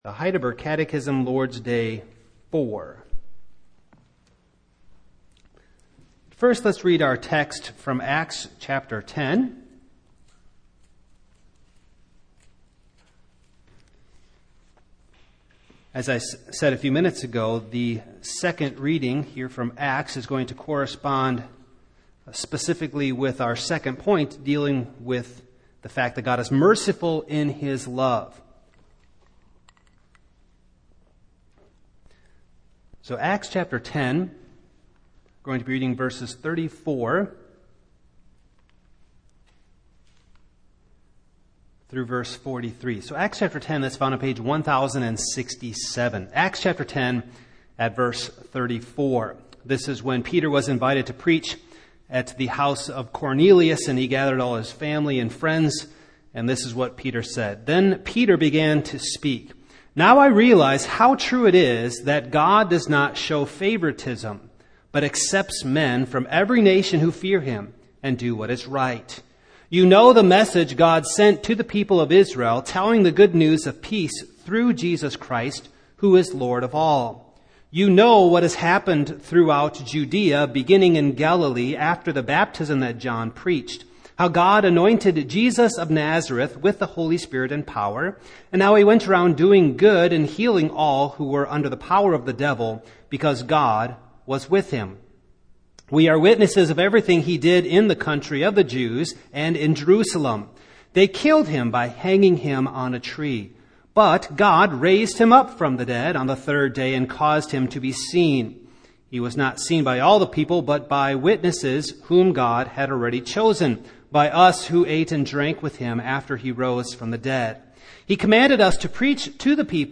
Passage: Acts 10:34-43 Service Type: Evening